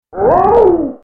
But the sound he makes when he's defeated is very different: